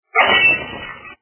Звук Звук - Кирпичем в окно